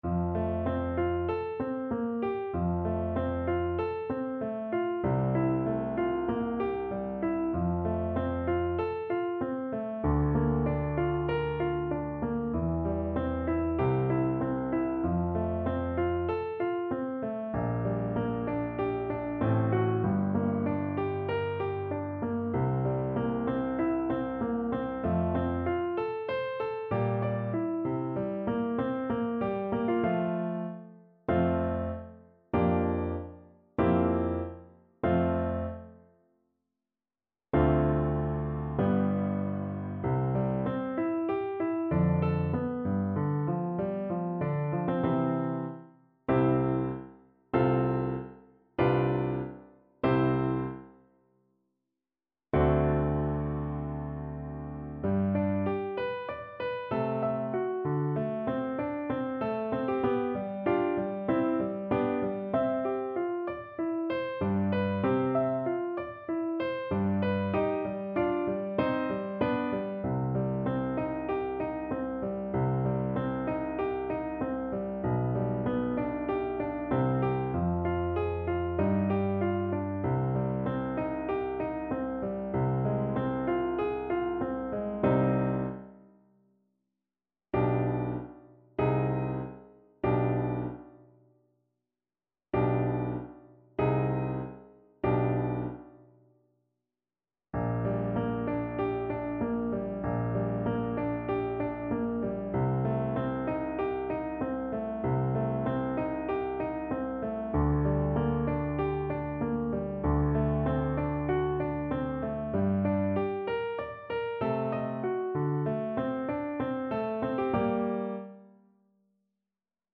~ = 96 Andante
Classical (View more Classical Violin Music)